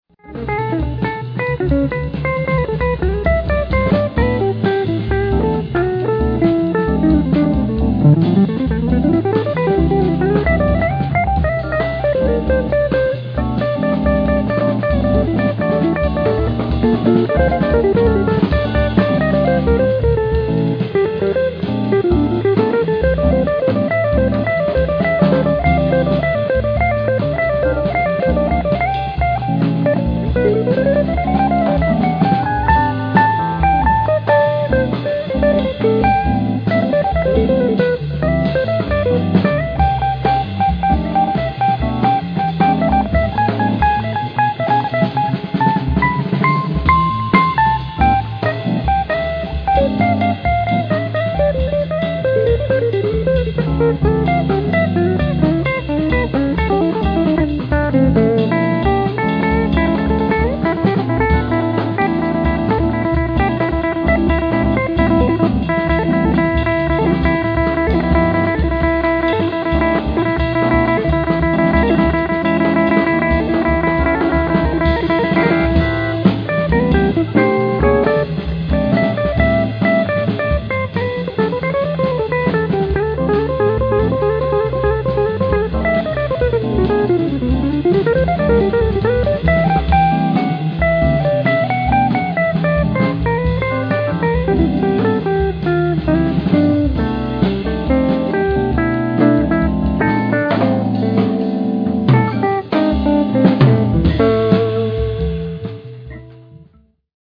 jazz guitarist
explores the organ trio format